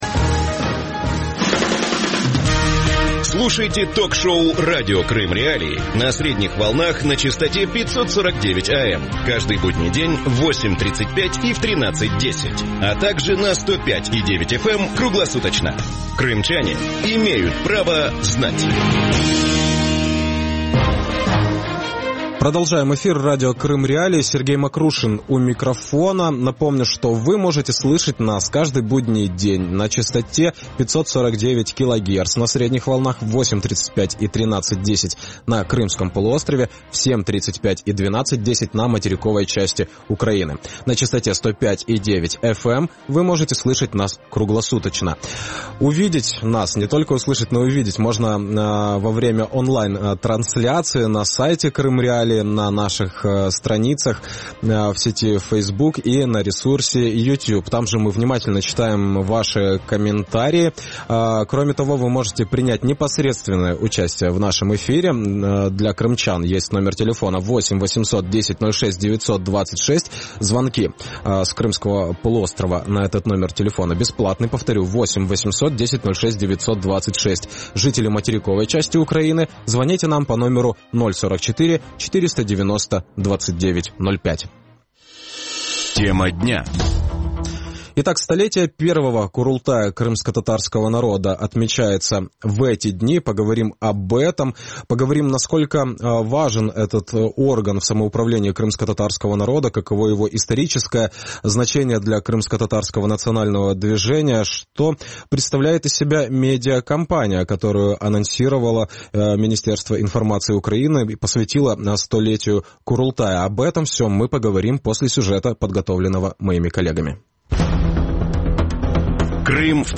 Что такое Курултай крымскотатарского народа? Каково его историческое значение для крымскотатарского национального движения? Что представляет из себя медийная кампания, которую Министерство информации Украины посвятило 100-летию Курултая? Гости эфира